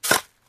fill_in_grave.ogg